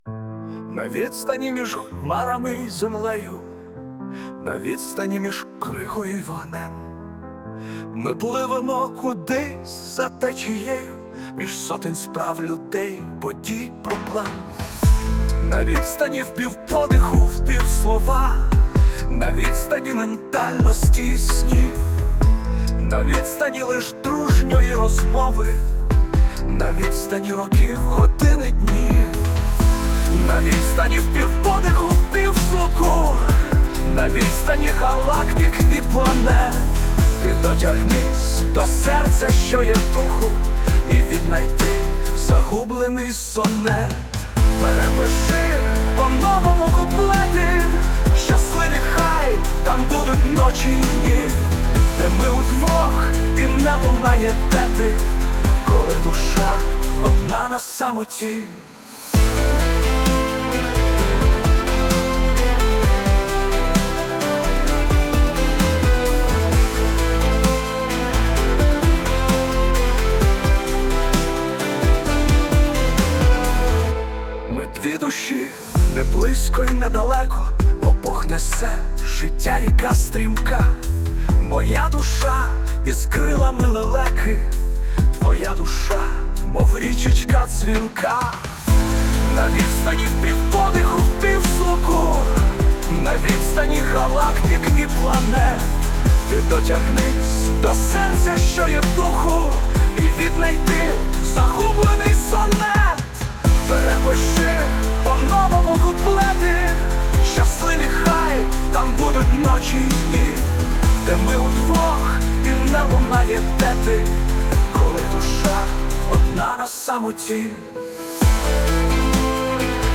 Пісня створена за допомогою ШІ.